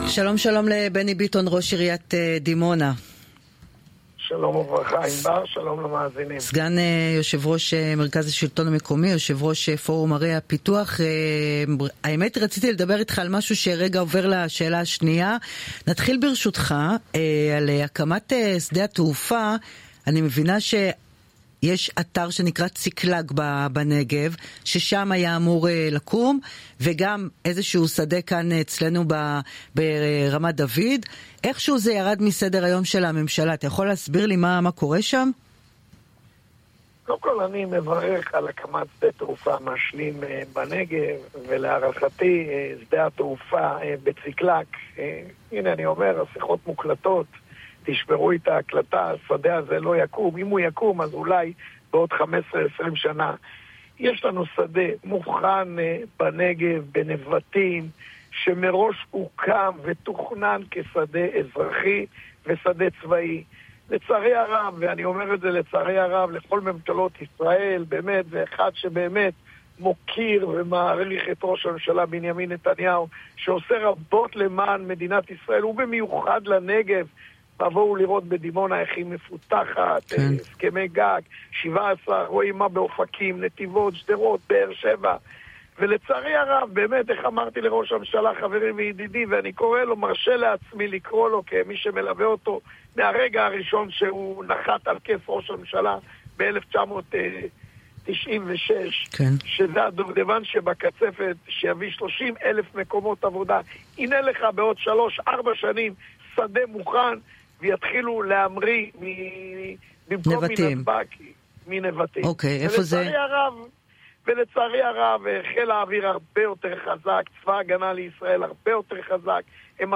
בראיון ל"רדיו חיפה", חשף ראש עיריית דימונה, בני ביטון, כי בחודש הבא ייחתם הסכם על הקדמת העתקת בזן למתחם ייעודי סמוך לדימונה.